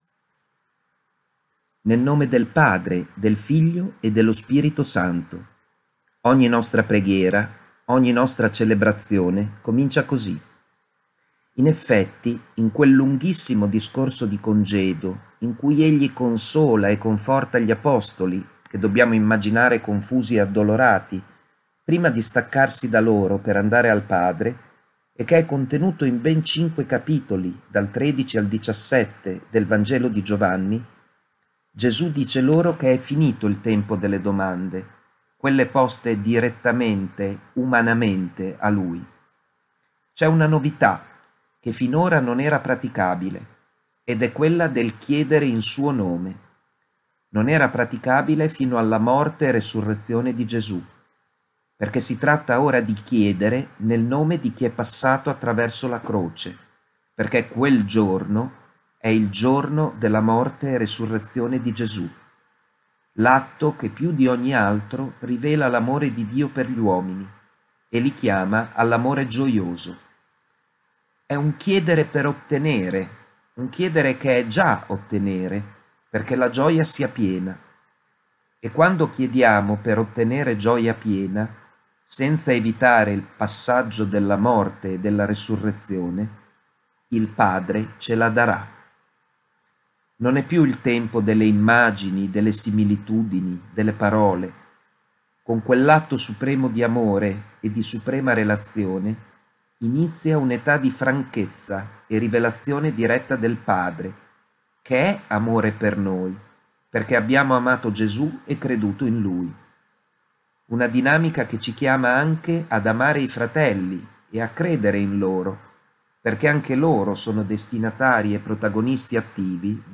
23/5/20 il commento biblico
Voci diverse si alternano per farci ascoltare la Parola di Dio, balsamo per i nostri cuori, in questi giorni di emergenza.